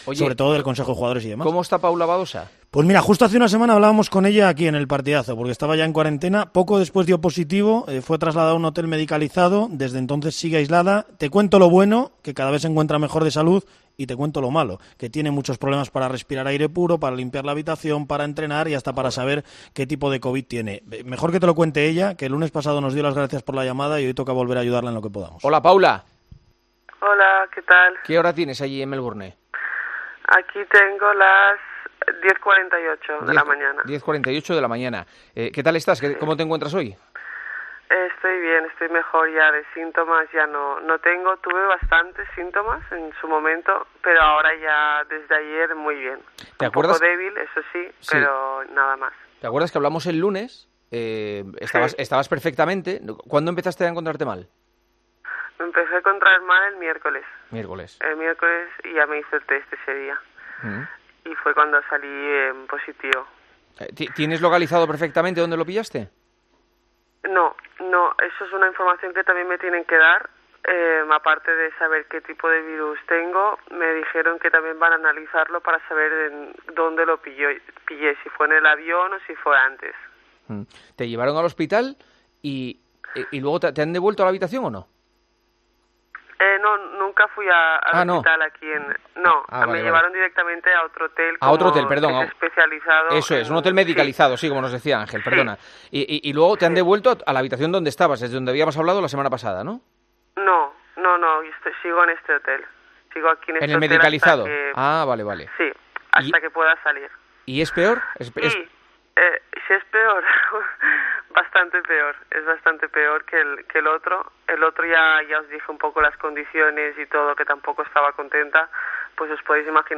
AUDIO: Hablamos con la tenista española, que ha dado positivo por coronavirus antes de de jugar el Open de Australia.